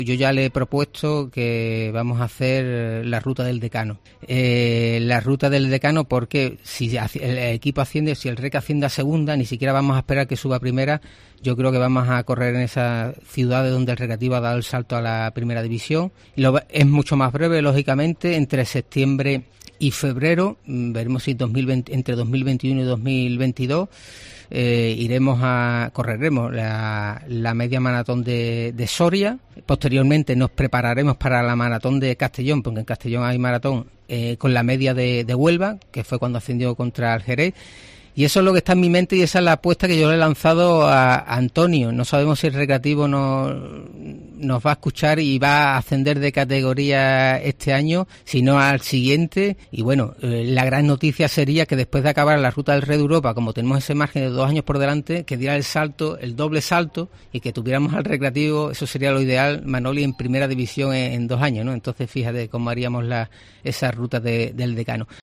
en los estudios de COPE